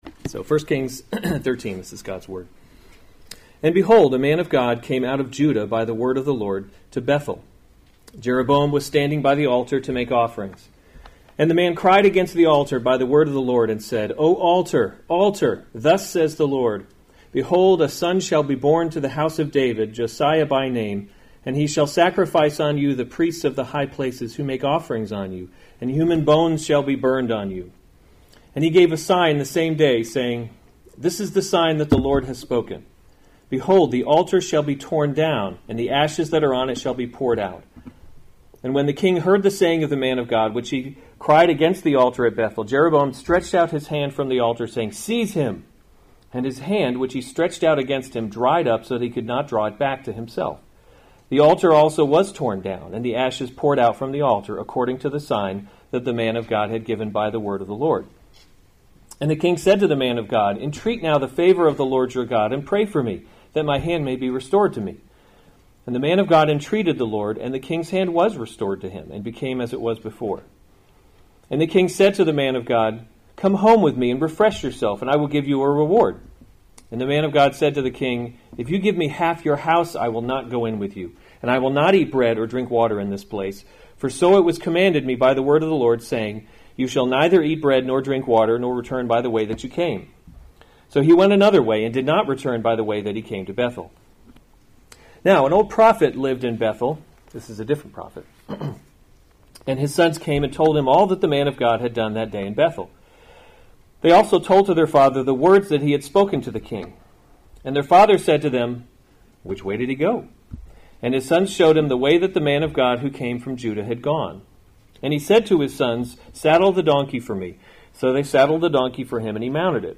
March 9, 2019 1 Kings – Leadership in a Broken World series Weekly Sunday Service Save/Download this sermon 1 Kings 13 Other sermons from 1 Kings A Man of God […]